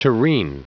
Prononciation du mot tureen en anglais (fichier audio)
Prononciation du mot : tureen